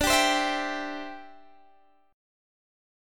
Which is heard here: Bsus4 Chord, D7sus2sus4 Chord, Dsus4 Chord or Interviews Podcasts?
D7sus2sus4 Chord